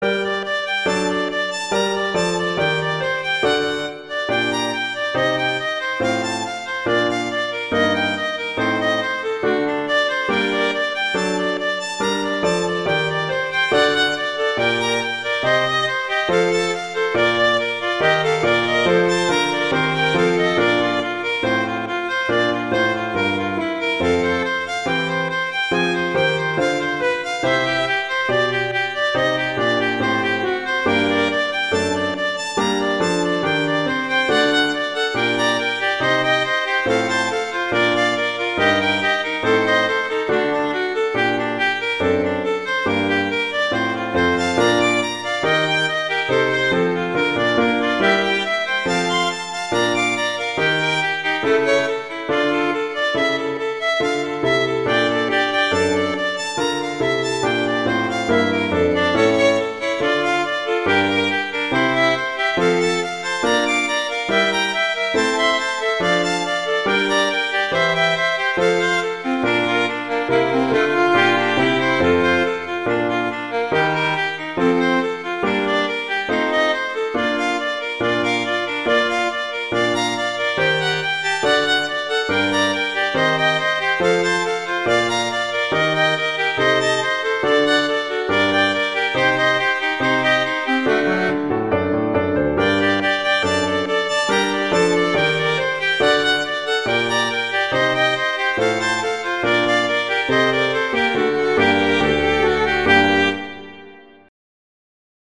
classical
G minor
Allegro